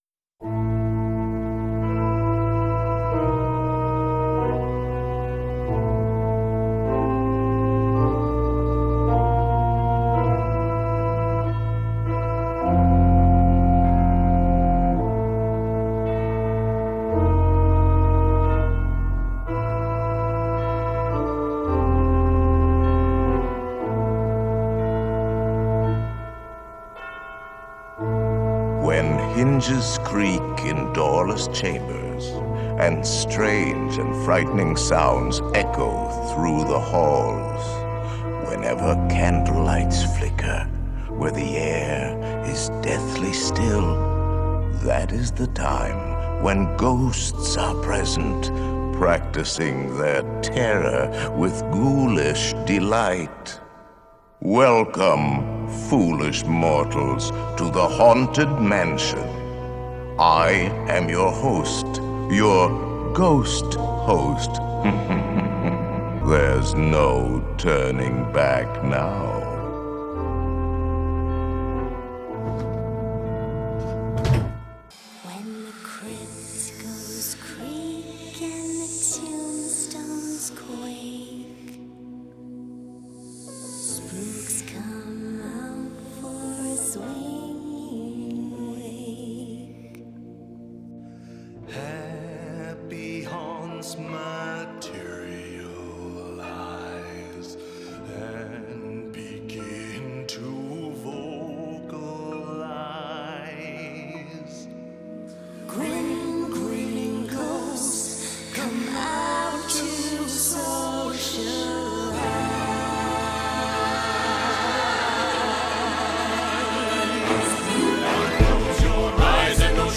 Custom audio pulled from various clips from the ride